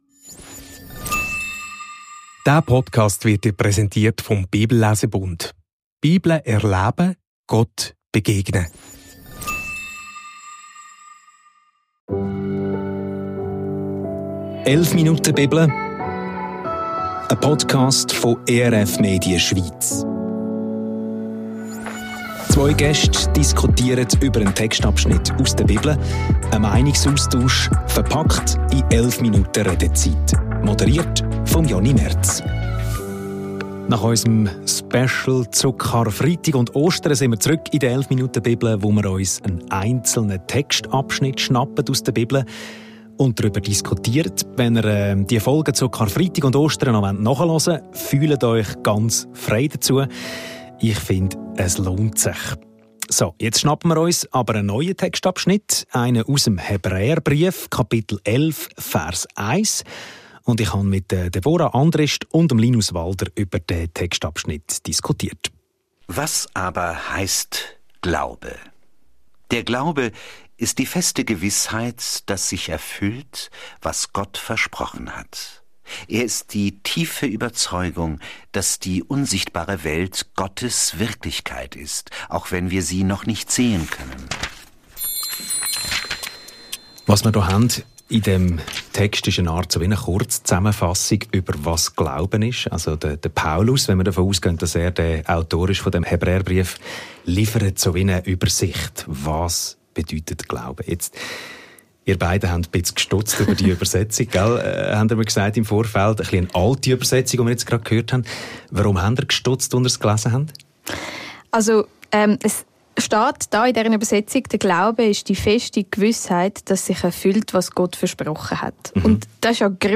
Ihr ahnt es: heute diskutieren wir über Glaubensfragen, respektive über die Bedeutung des Begriffs.